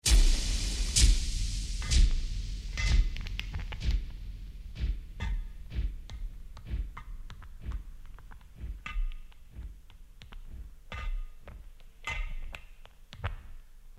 Play, download and share Burst repeating original sound button!!!!
001-burst-repeating.mp3